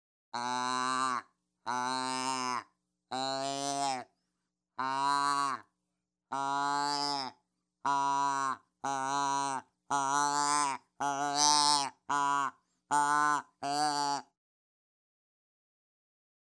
Il produit les sons authentiques du mâle, de la femelle et même du faon.